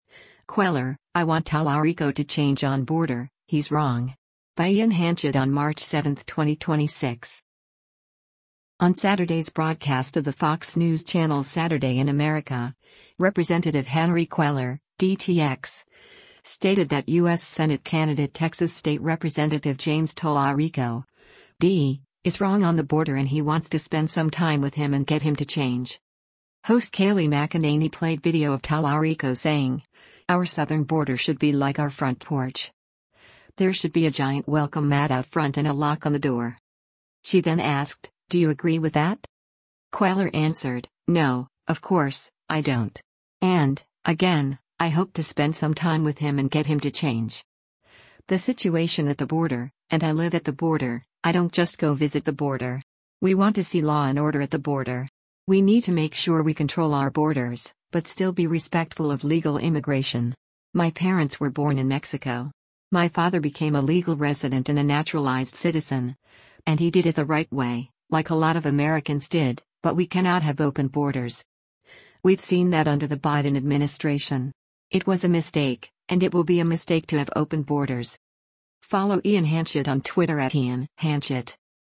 On Saturday’s broadcast of the Fox News Channel’s “Saturday in America,” Rep. Henry Cuellar (D-TX) stated that U.S. Senate candidate Texas state Rep. James Talarico (D) is wrong on the border and he wants “to spend some time with him and get him to change.”
Host Kayleigh McEnany played video of Talarico saying, “Our southern border should be like our front porch. There should be a giant welcome mat out front and a lock on the door.”